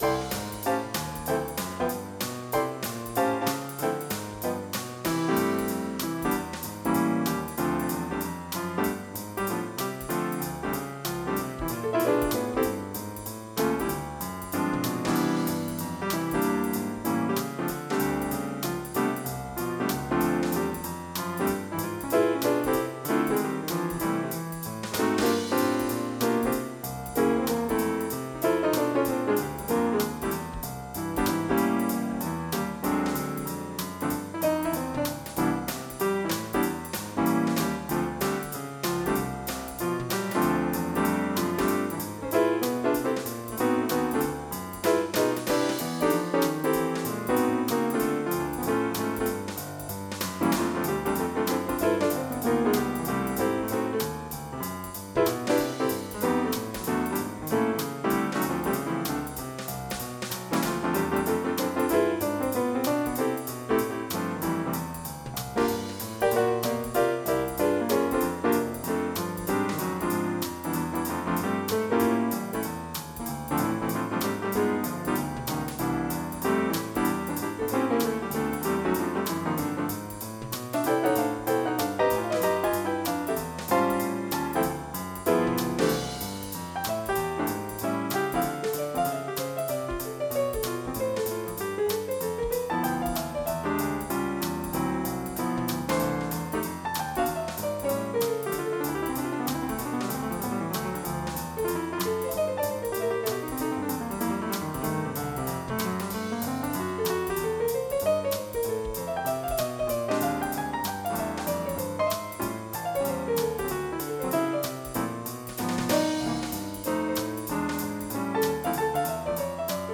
JAZZ28.mp3